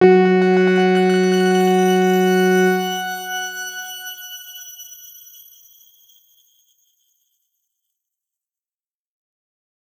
X_Grain-F#3-ff.wav